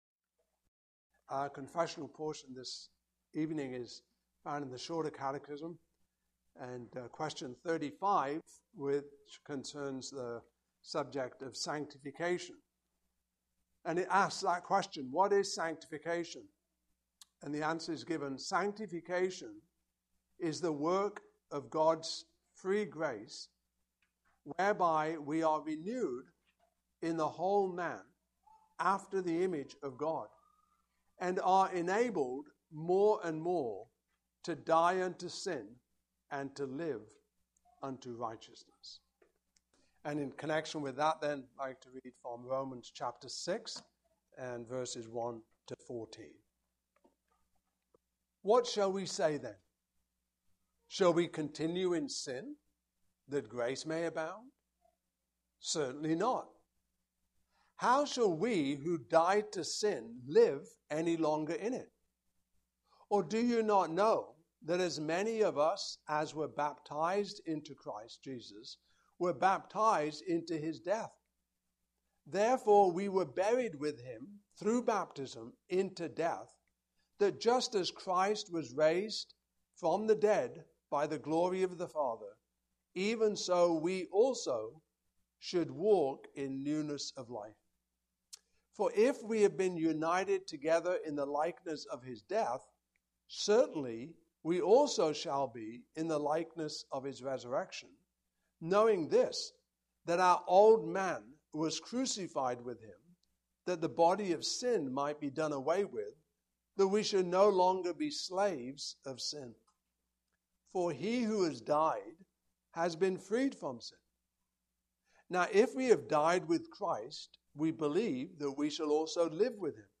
Westminster Shorter Catechism Passage: Romans 6:1-14 Service Type: Evening Service Topics